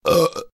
sound_click_alt.ogg